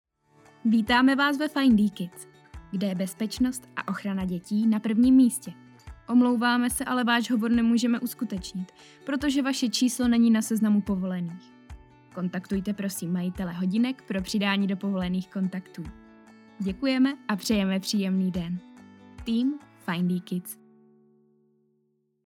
Profesionální ženský hlas - voiceover/dabing (do 400 znaků)
Findee kids s hudbou.mp3